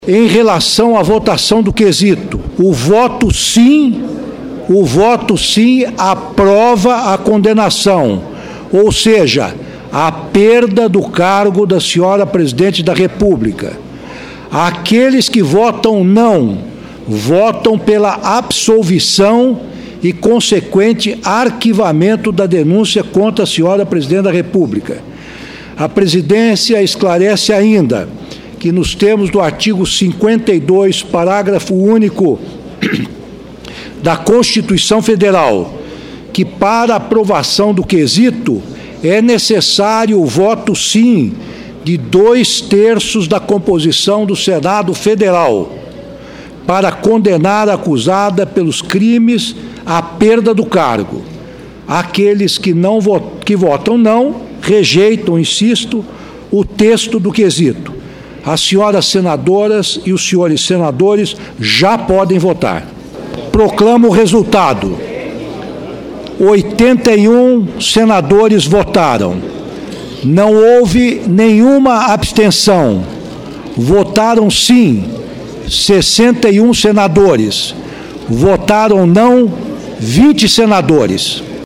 Ouça o áudio no qual o presidente do processo de impeachment, Ricardo Lewandowski, dá as orientações sobre a votação e, posteriormente, proclama o resultado.